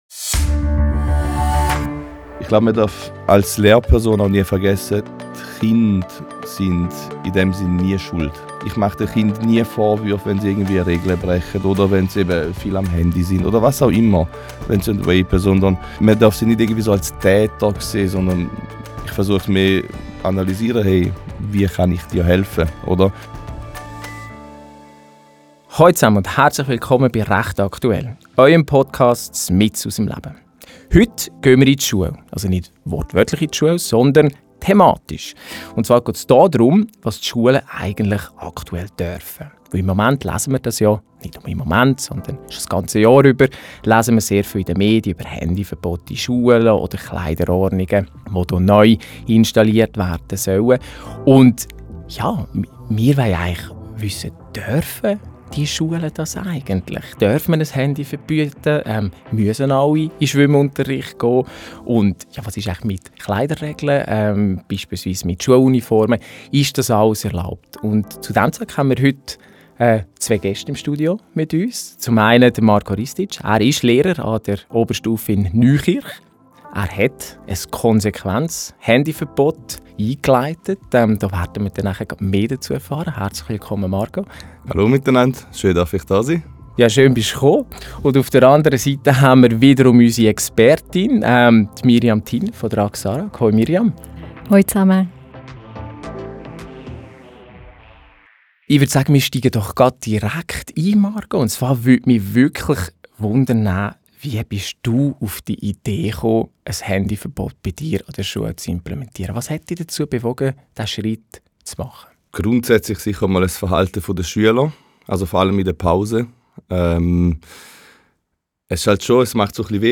In der ersten Folge stellen wir diese Fragen drei jungen Frauen, die zusammen in einer WG leben.